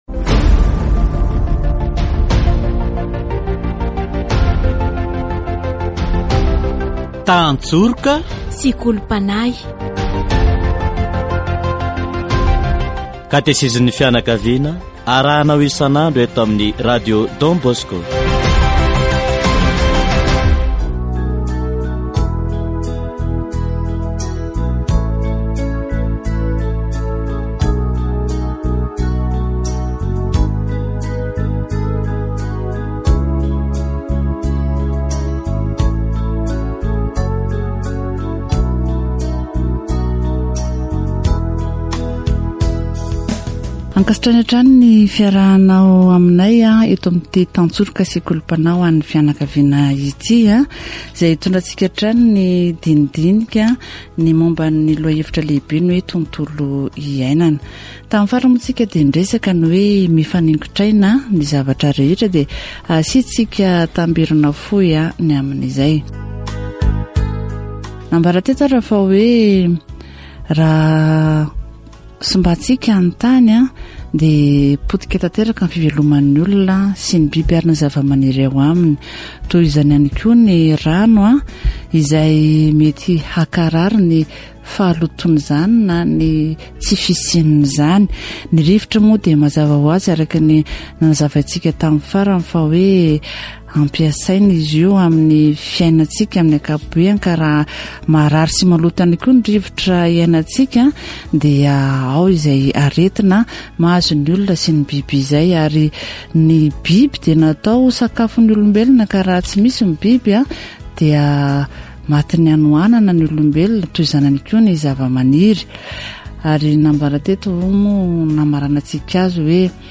Catechesis on the environment